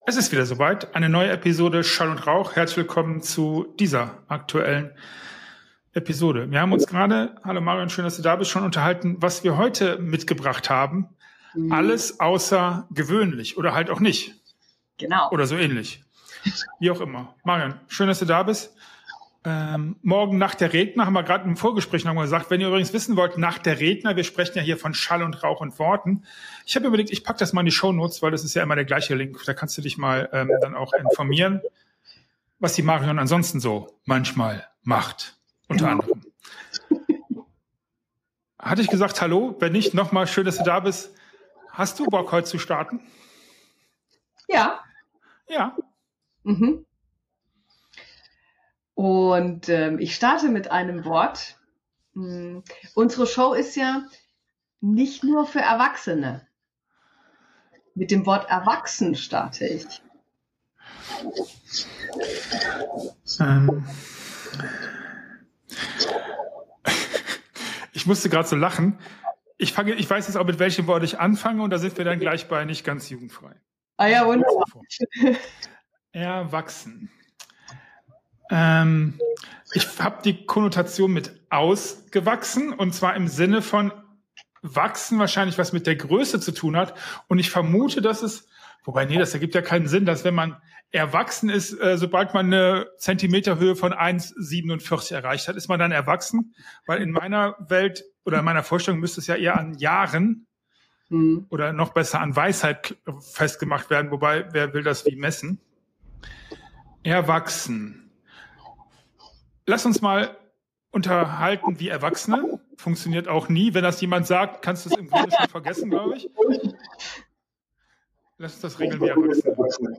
Wieso ein Desaster wortwörtlich ein gefallener Stern ist und was das für unser Verständnis von Unglück bedeutet. Freu dich auf spannende Fakten, humorvolle Anekdoten und tiefgründige Diskussionen.